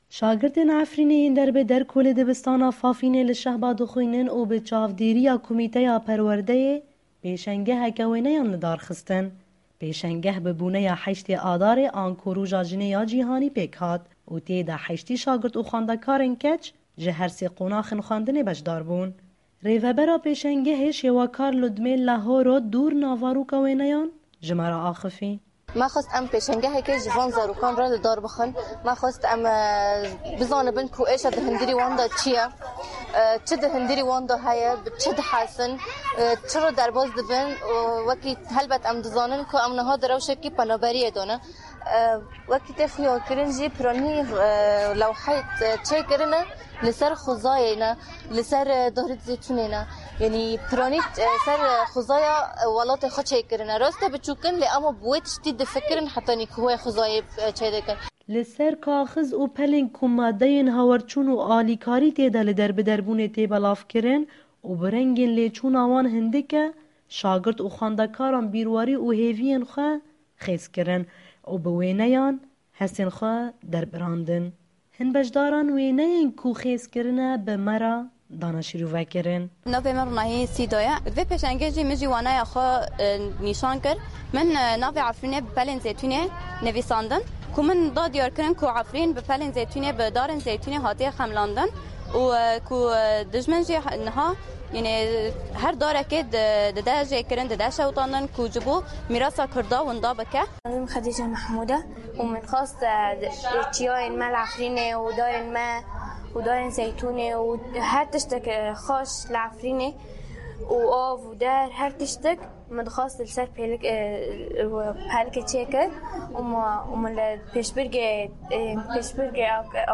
Zarokên Efrînê ku bi wêneyên xwe beşdarî pêşangehê bûn bi sitranên folklora Efrînê ew bername xemilandin.